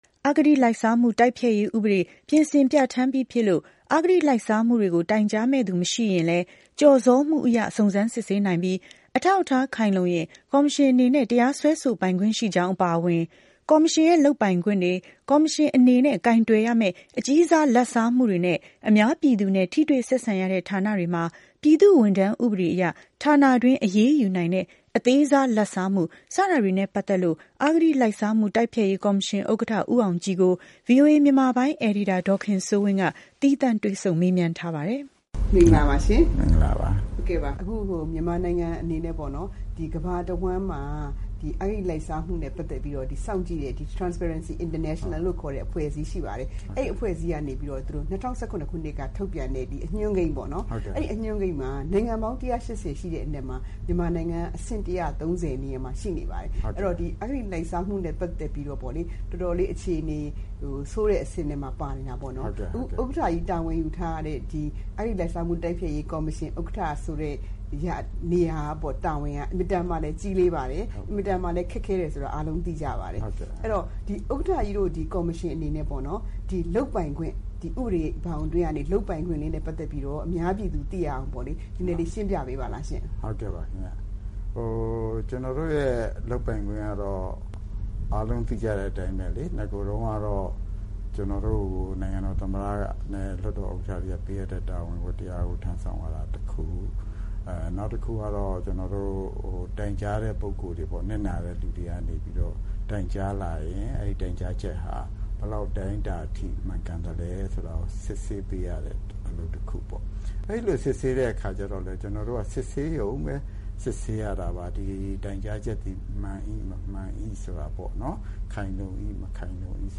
သီးသန့်တွေ့ဆုံမေးမြန်းထားပါတယ်။